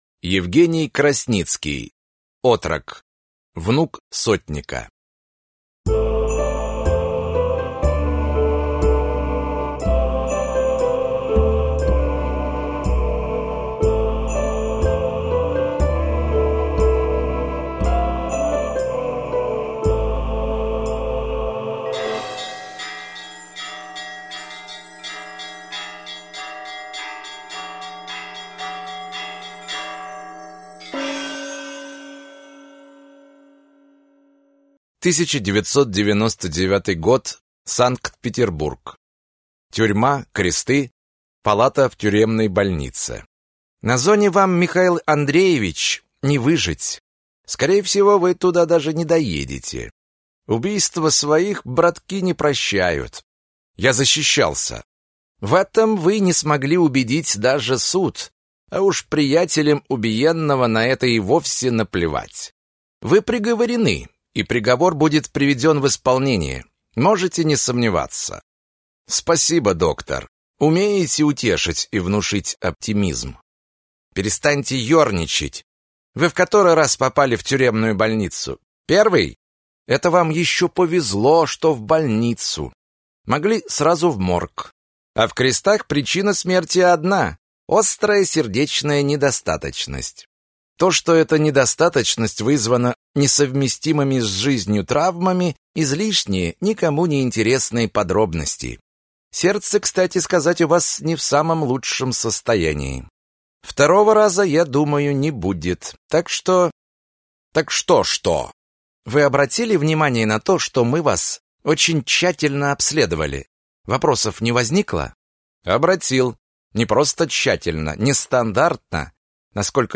Аудиокнига Внук сотника - Красницкий Евгений Сергеевич - Скачать книгу, слушать онлайн